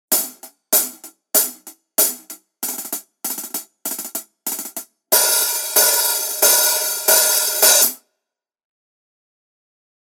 Тарелки серии Custom обладают широким частотным диапазоном, теплым плотным звуком и выдающейся музыкальностью.
Masterwork 14 Custom Rock Hats sample
Custom-Hihat-14-Rock.mp3